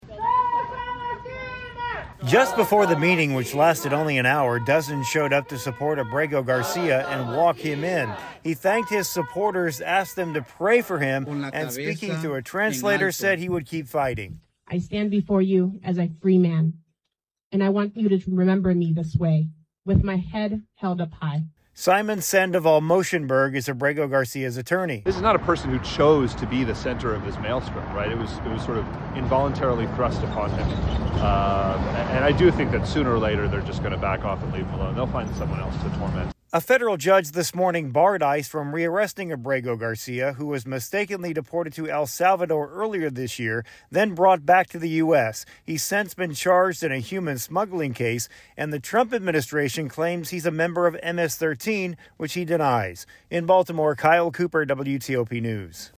reports on the latest development surrounding the case of mistakenly deported Maryland man Kilmar Abrego Garcia.